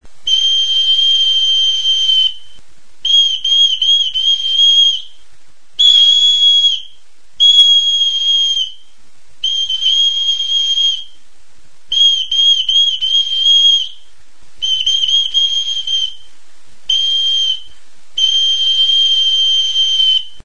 Enregistr� avec cet instrument de musique.
Vidéo Audio TXULUBITA JOALDIA.
Instruments de musique: TXULUBITA Classification: Aérophones -> Flûtes -> Bestelakoak Emplacement: Erakusketa biltegia; taldeak Explication de l'acquisition: Egina Description: Abrikot hezur zulatua da.